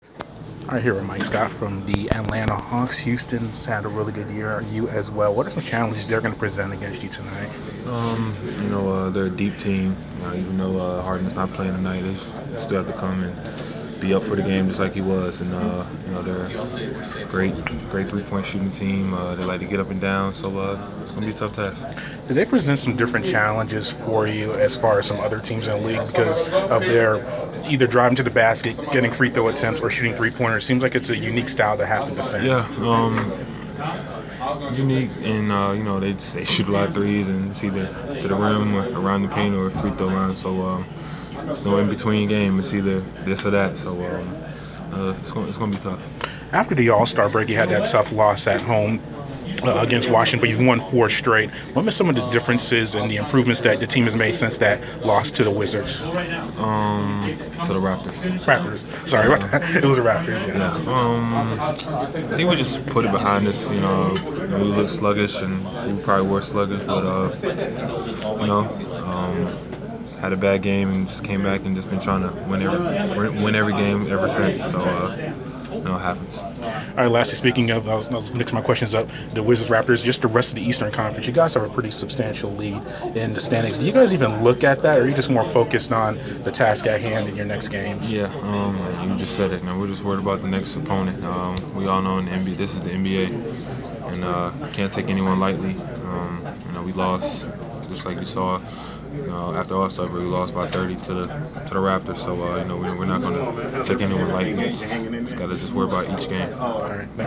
Inside the Inquirer: Pregame interview with Atlanta Hawk Mike Scott (3/3/15)